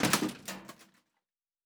Metal Foley Impact 5.wav